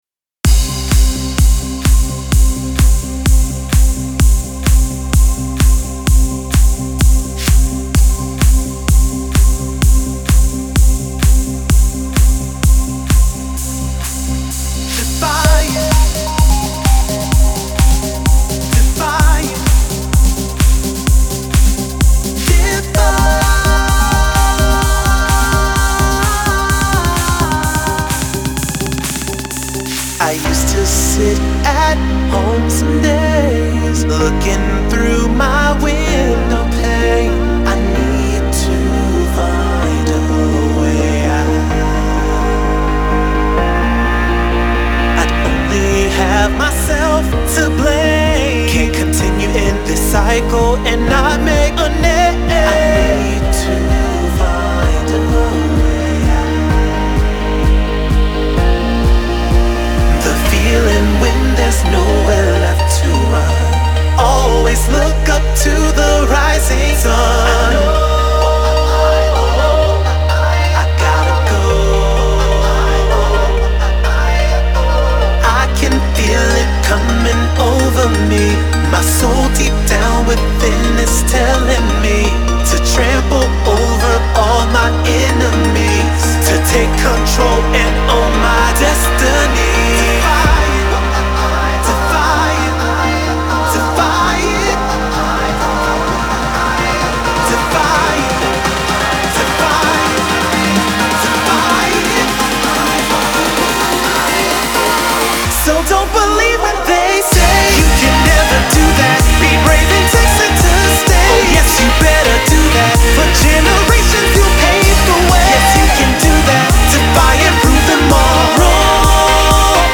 Debut EP by Pop/R&B recording artist